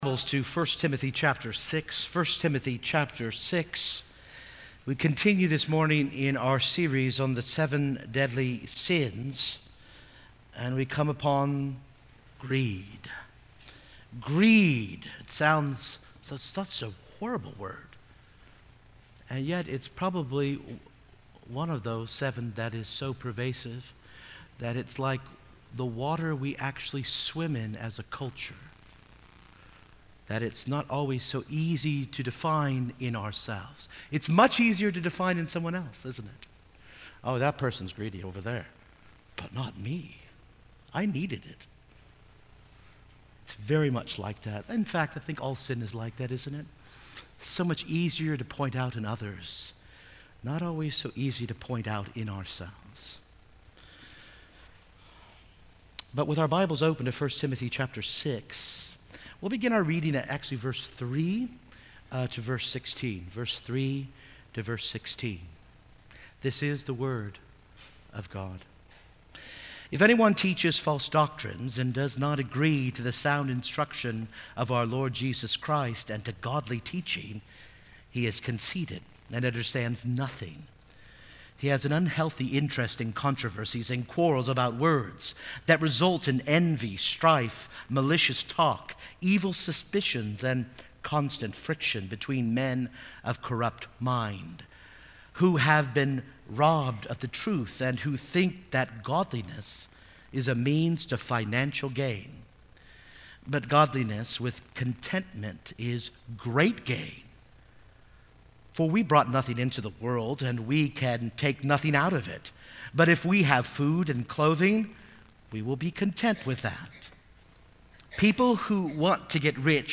The messages from the morning and evening services at Second Christian Reformed Church of Kalamazoo, MI.
Sermon